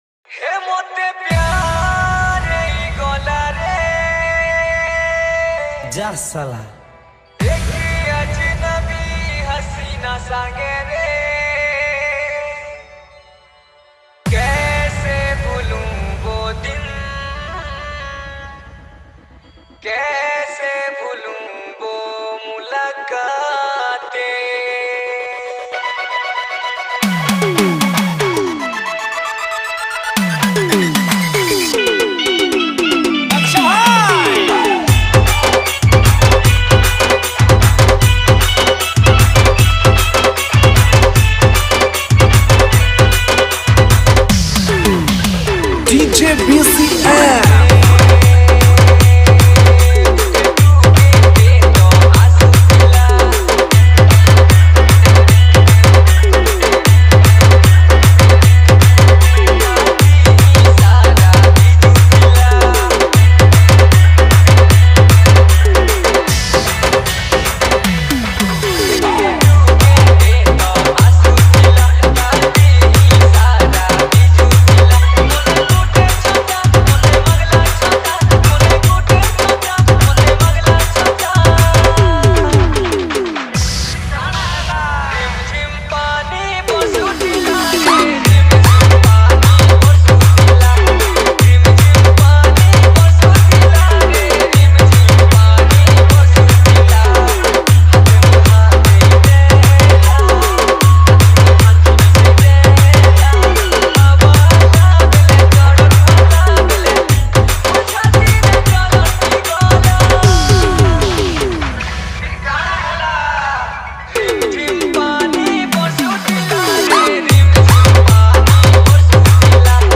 Category:  New Sambalpuri Dj Song 2023